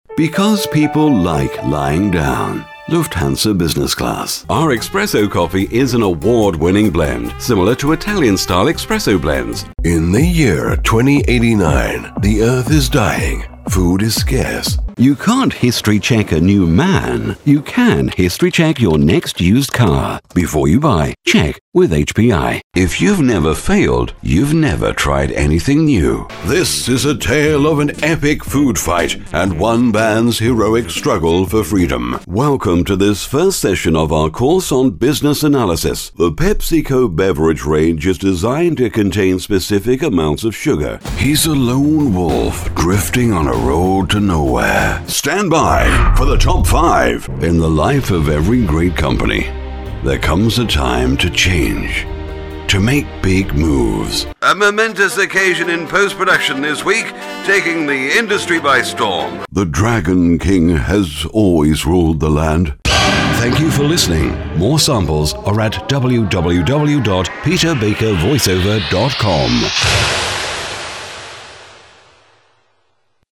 Sprechprobe: Werbung (Muttersprache):
About me: I am a professional voiceover with a rich but clear, resonant, authorative voice. I have a standard English accent, but am very flexible in styles. I have my own broadcast quality studio with a Neumann U87 & TLM 103 microphones and offer fast turnarounds on recording.